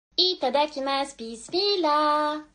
ittadakima bismillah Meme Sound Effect
ittadakima bismillah.mp3